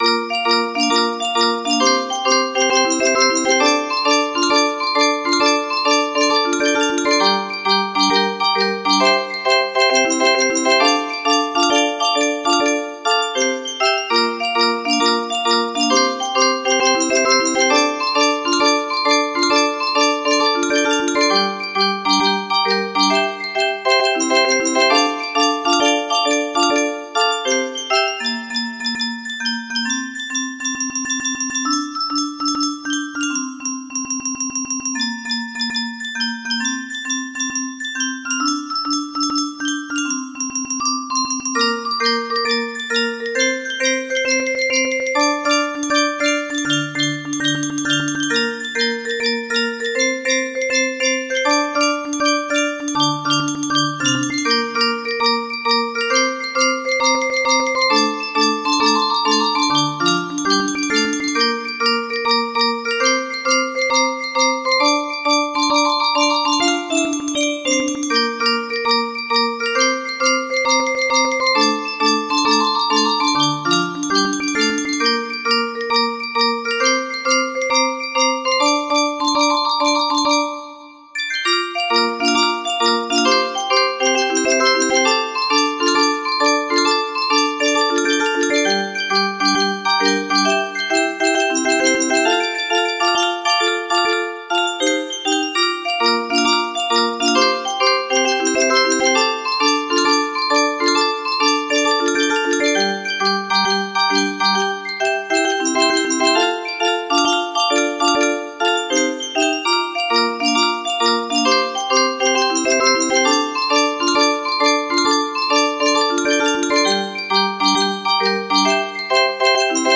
Untitled music box melody
Full range 60